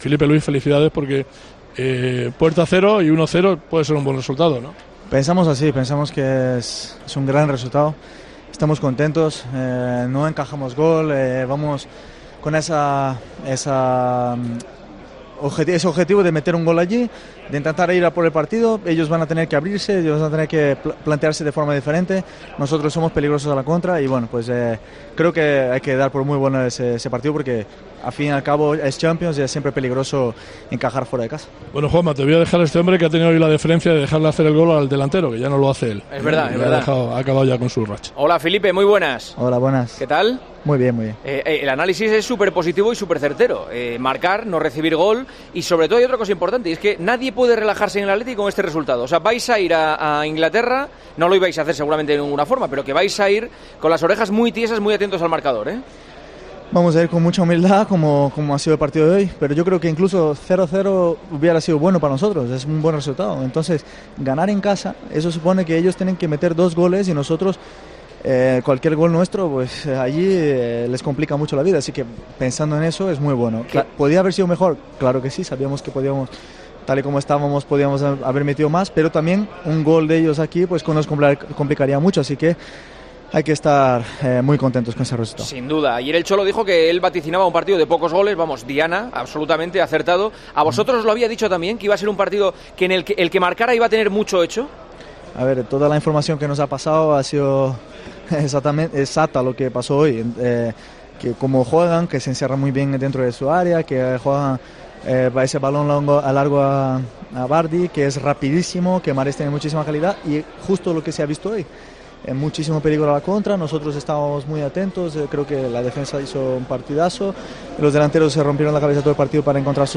Entrevista En El Partidazo